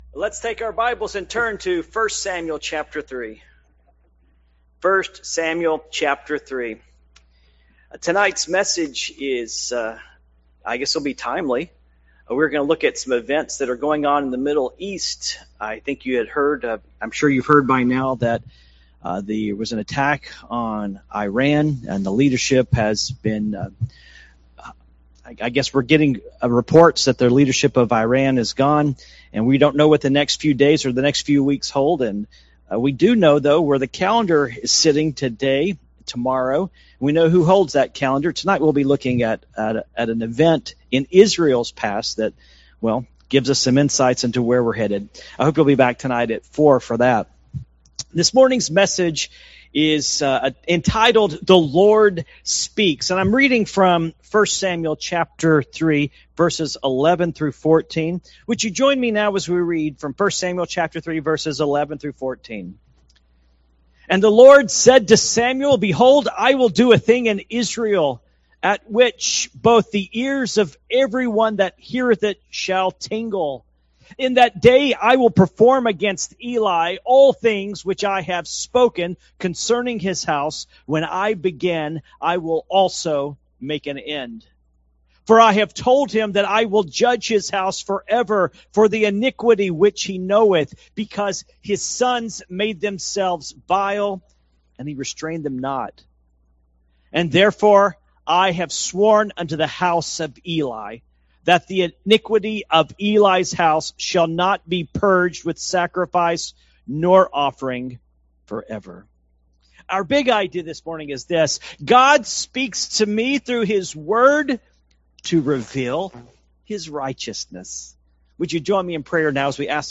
Passage: 1 Samuel 3:11-14 Service Type: Morning Worship